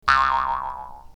bounce2.mp3